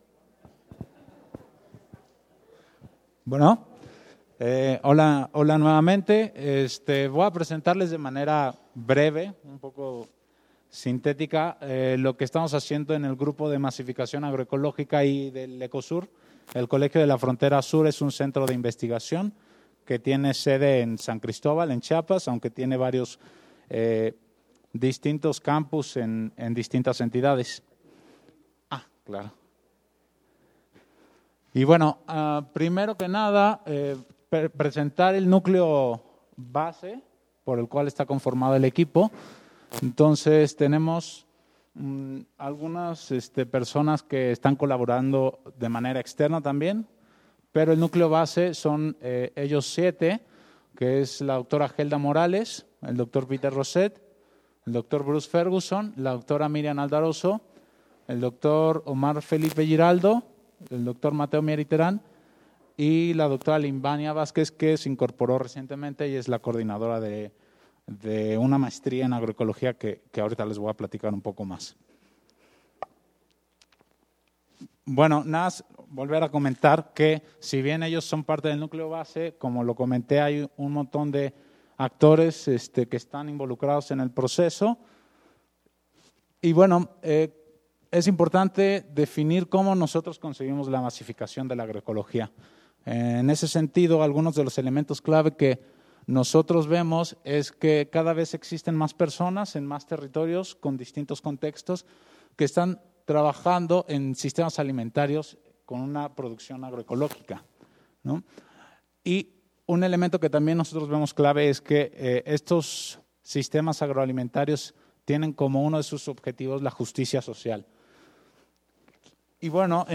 Presentación ECOSUR – Andes CDP
Expositor: Grupo de investigación sobre la Masificación de la Agroecología – ECOSUR